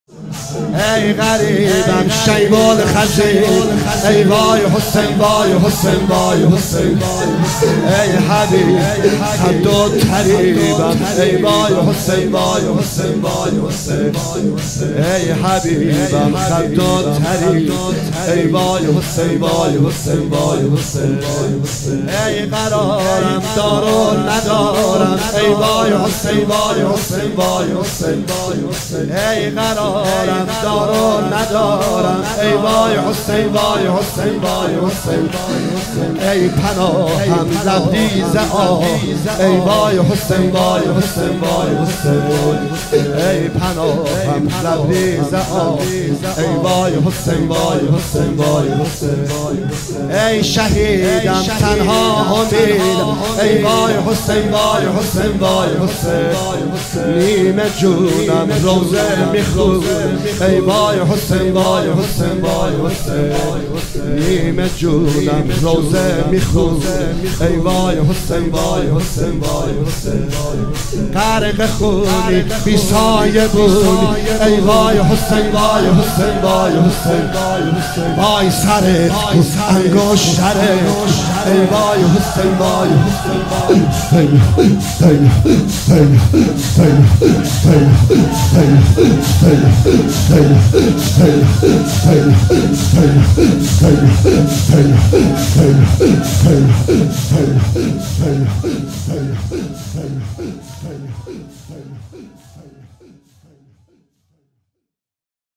مداحی نریمان پناهی | شهادت حضرت رقیه (س) | غم خانه بی بی شهر بانو | پلان 3